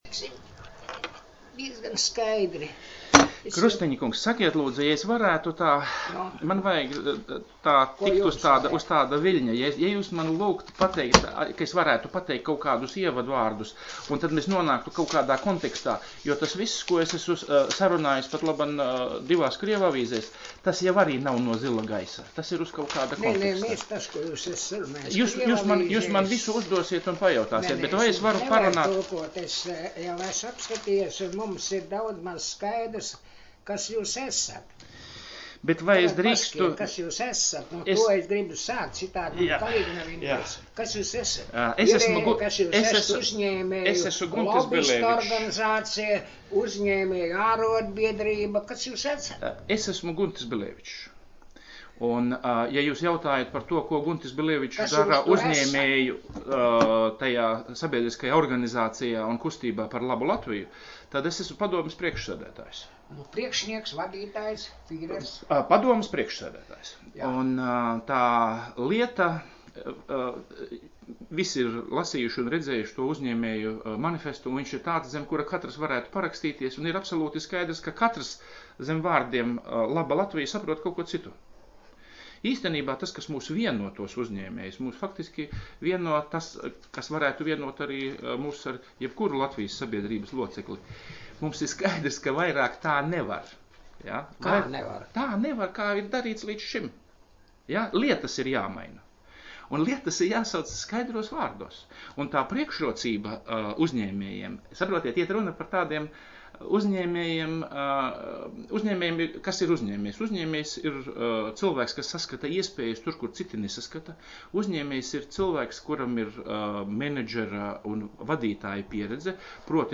Saruna “Latvijas avīzē” .